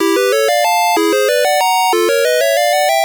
One of the jingles that plays at the start of a level